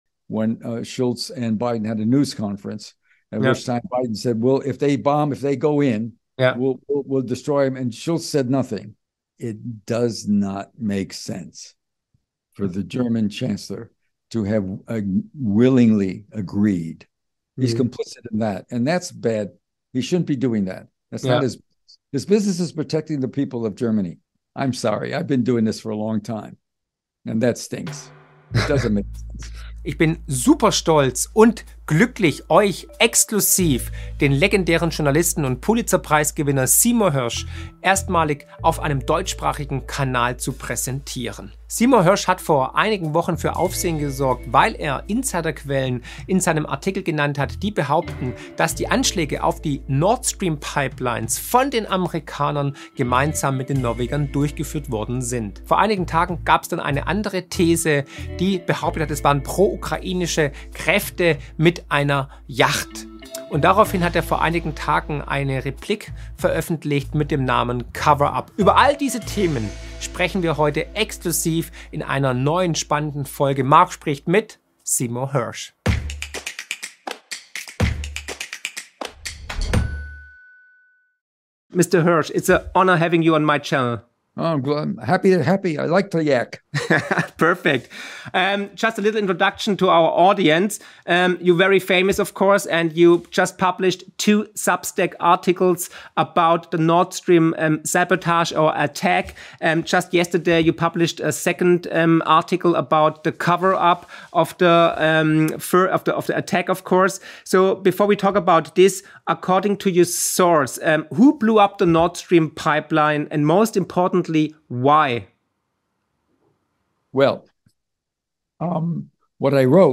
Seymour Hersh: Wer hat Nord Stream gesprengt? (Interview)